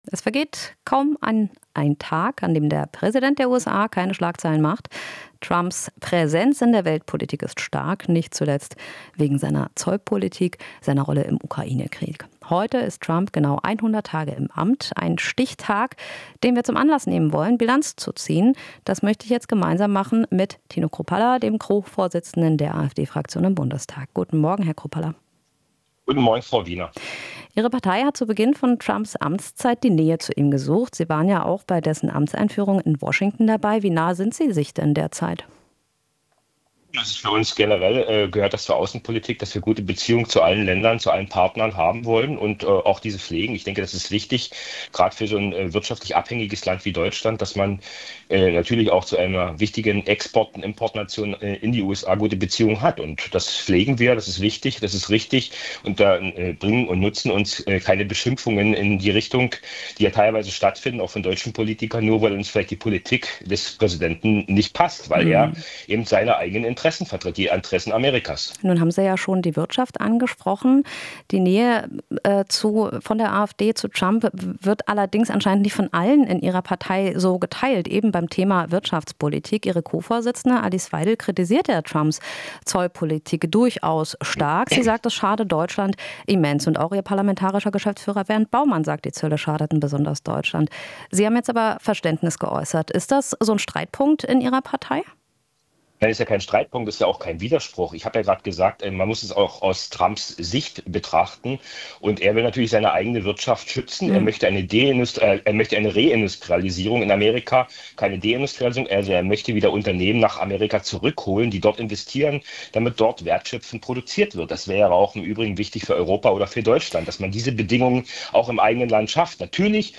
Interview - Chrupalla (AfD): Trump vertritt die Interessen der USA